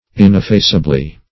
ineffaceably - definition of ineffaceably - synonyms, pronunciation, spelling from Free Dictionary Search Result for " ineffaceably" : The Collaborative International Dictionary of English v.0.48: Ineffaceably \In`ef*face"a*bly\, adv.
ineffaceably.mp3